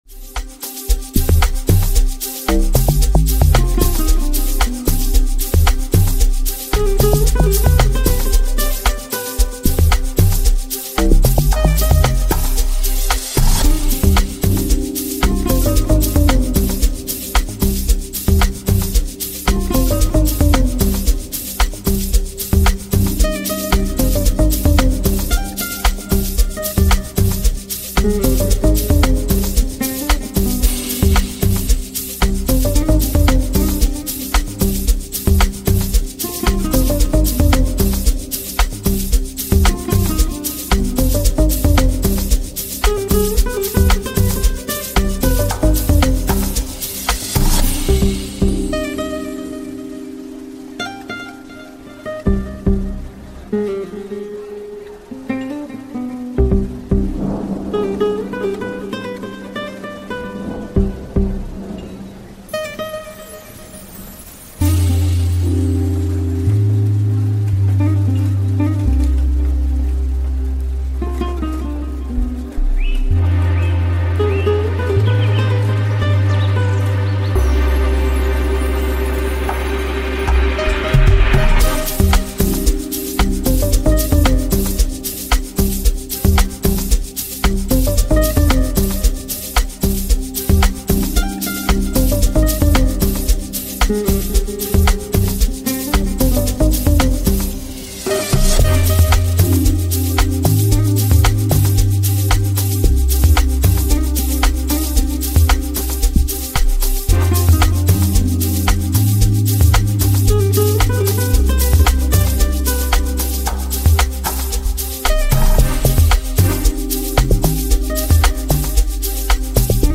Private School Amapiano